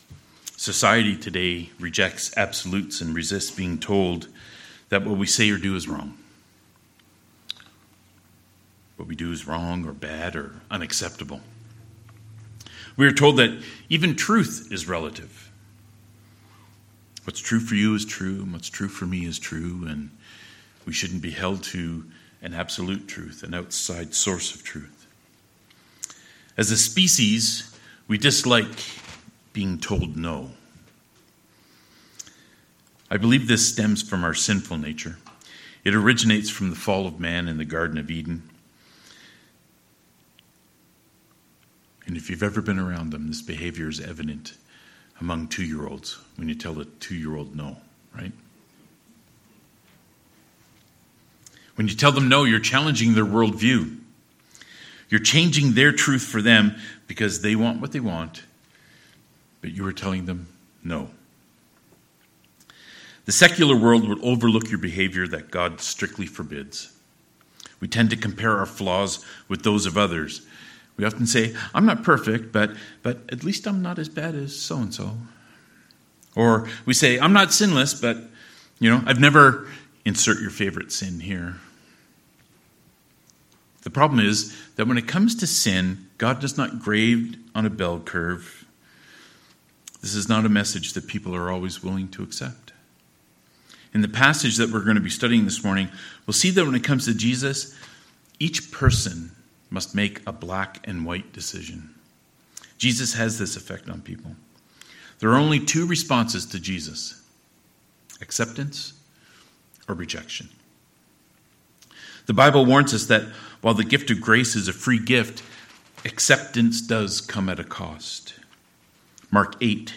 Passage: John 11: 45-57 Service Type: Sermons